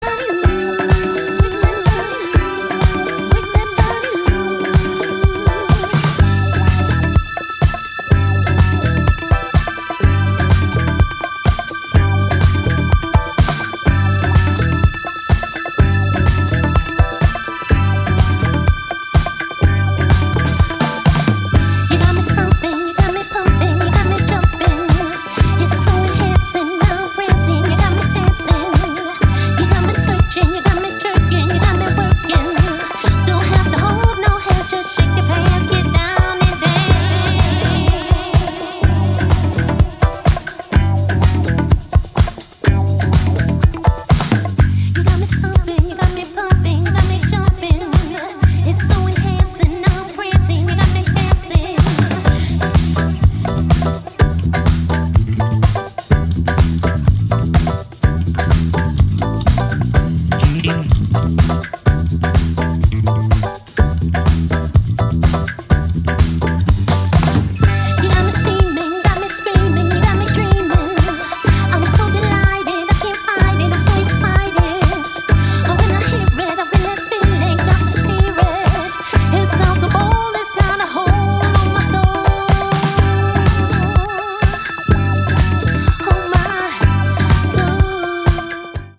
パーティ感溢れるさすがの一枚ですね！